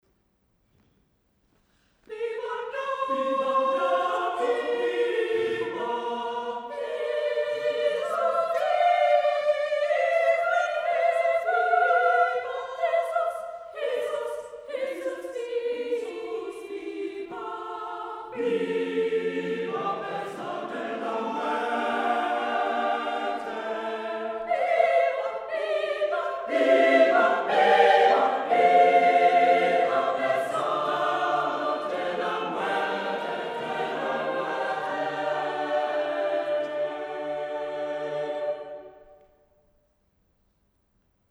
Cherwell Singers
Holy Rood Catholic Church, Abingdon Road, Oxford